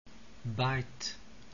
Diphthongs
Open to close, front bite
BITE.wav